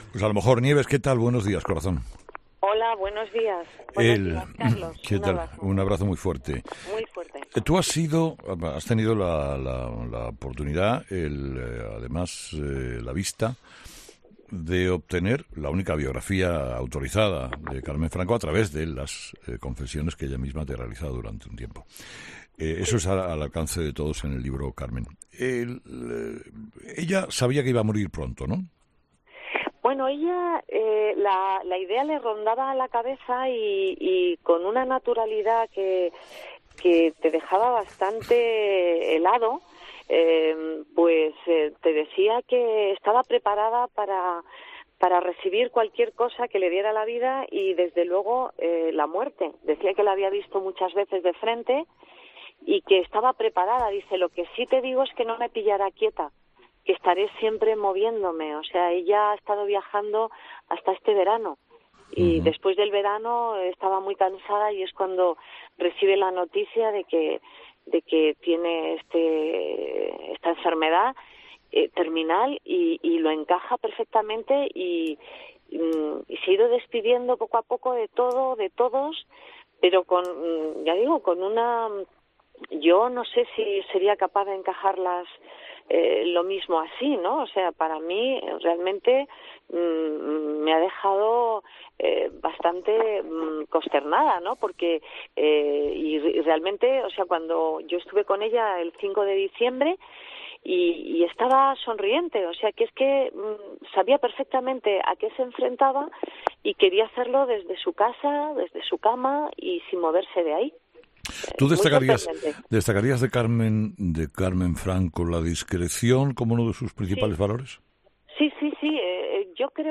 Nieves Herrero habla sobre Carmen Franco en 'Herrera en COPE'